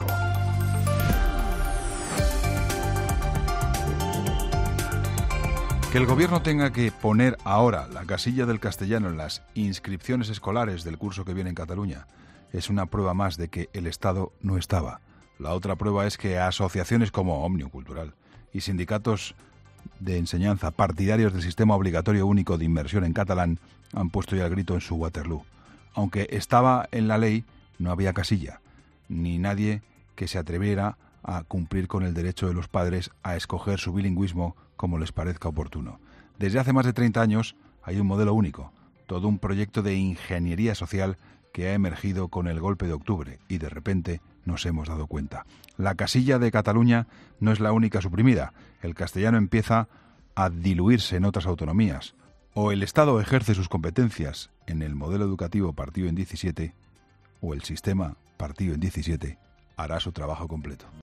AUDIO: Escucha el comentario del director de 'La Linterna'